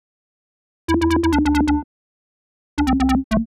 Index of /musicradar/uk-garage-samples/136bpm Lines n Loops/Synths